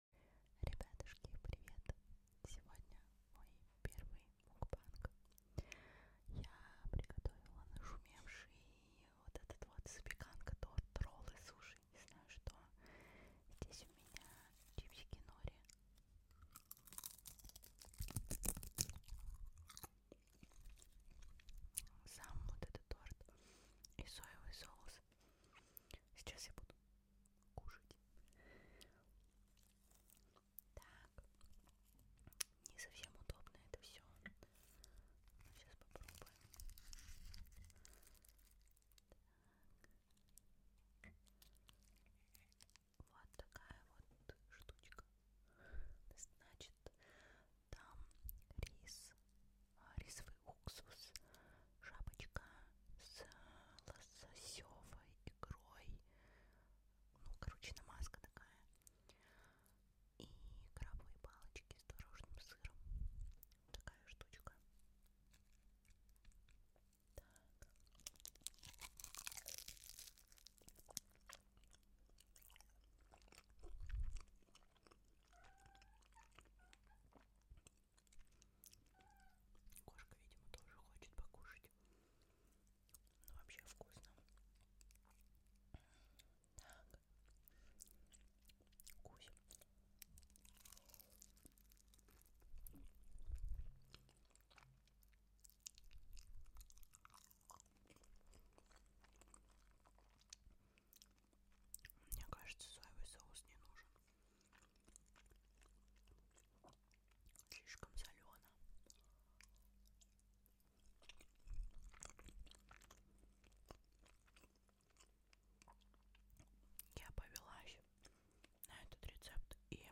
🍱ASMR MUKBANG🍣 И наверное последний sound effects free download